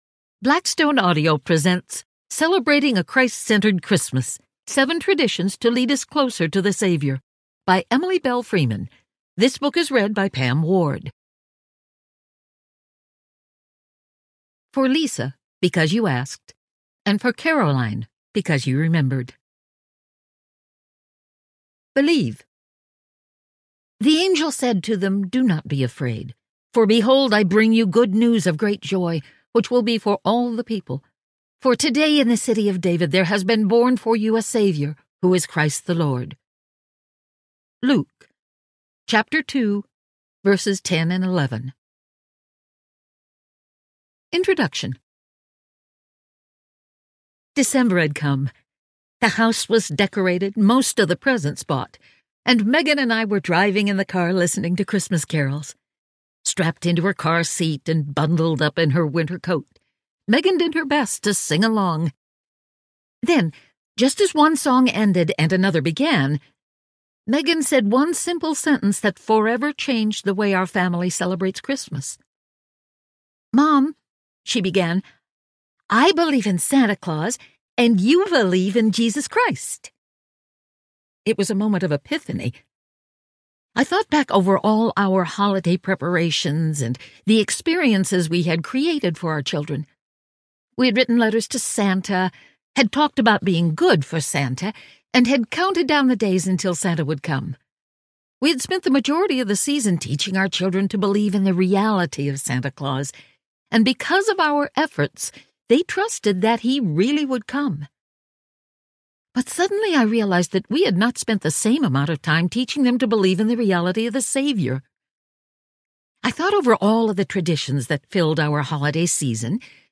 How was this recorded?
1.5 Hrs. – Unabridged